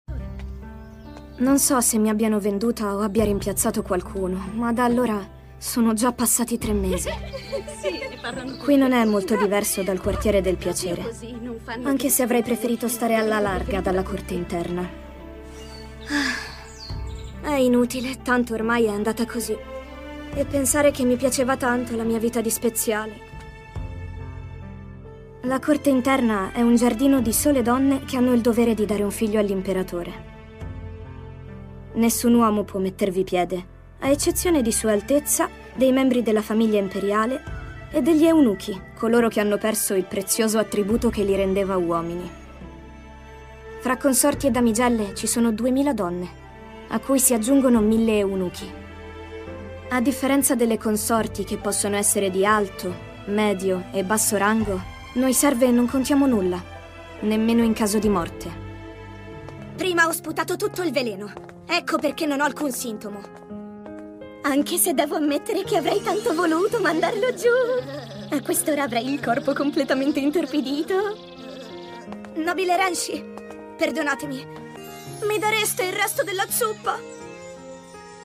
nel cartone animato "Il monologo della speziale", in cui doppia Mao Mao.